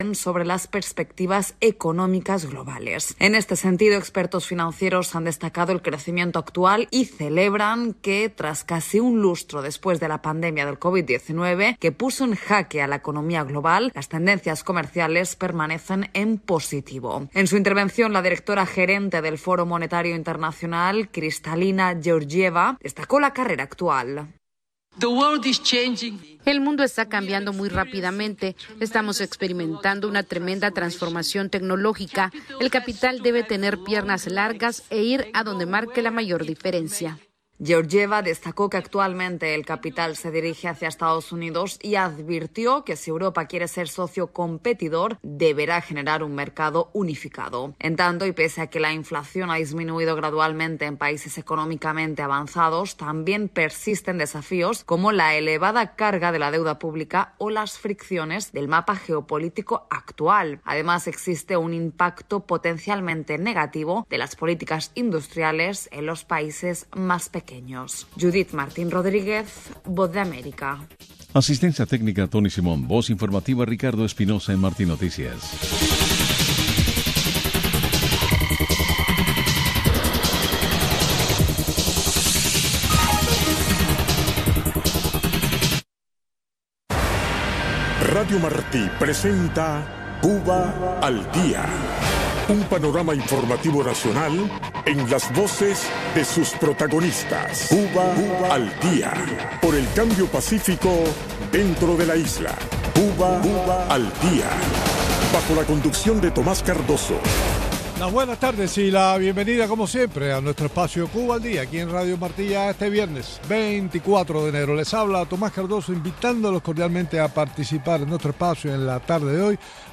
conecta cada día con sus invitados en la isla en este espacio informativo en vivo